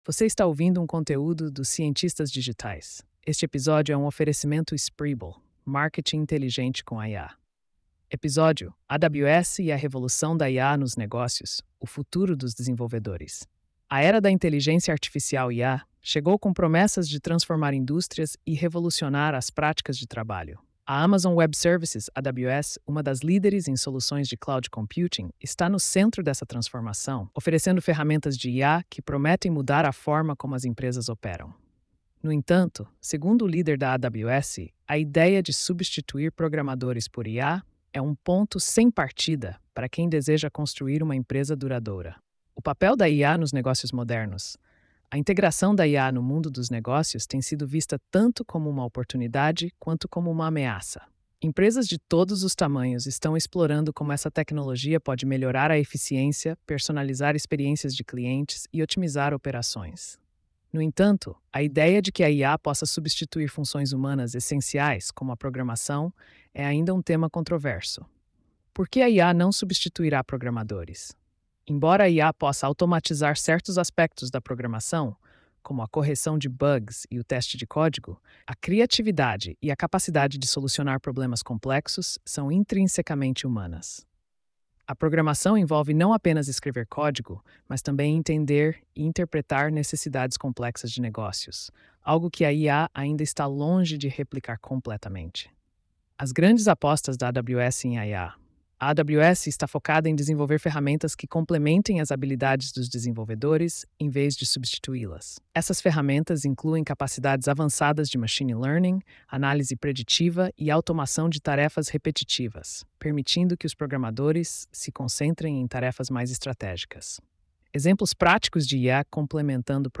post-4602-tts.mp3